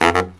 LOHITSAX05-L.wav